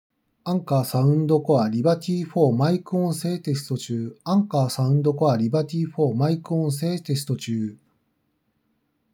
✅「Anker Soundcore Liberty 4」のマイク音声テスト
うるさい場所だと多少の騒音は入りますが、それでも相手の音声はしっかり聞き取れるレベルです。